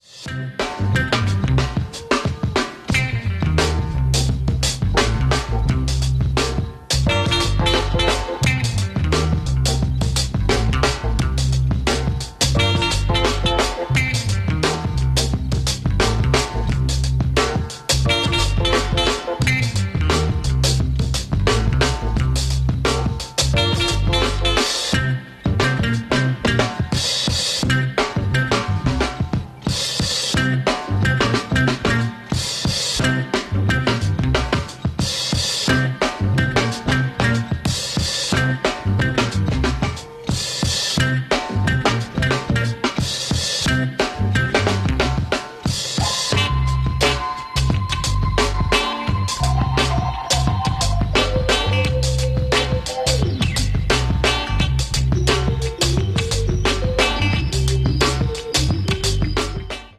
Spotted four beautiful peacocks on sound effects free download
Spotted four beautiful peacocks on my way home.